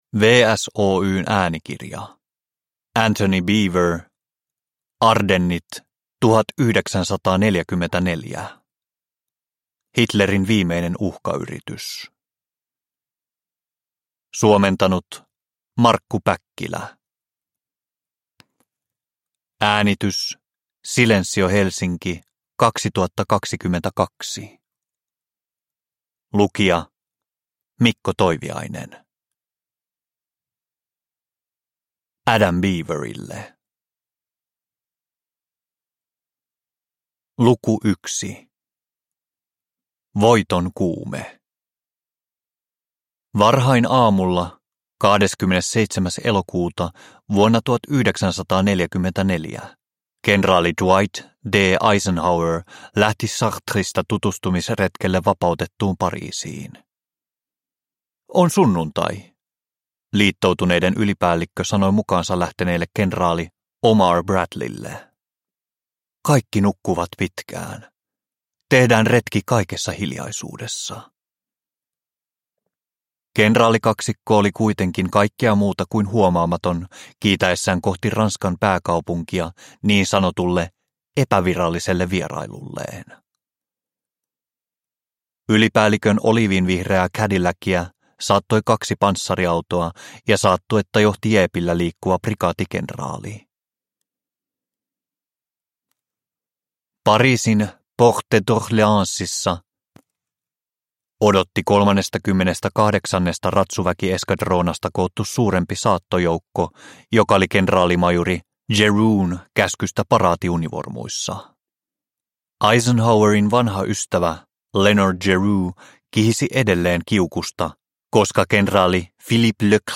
Ardennit 1944 – Ljudbok – Laddas ner